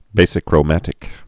(bāsĭ-krō-mătĭk)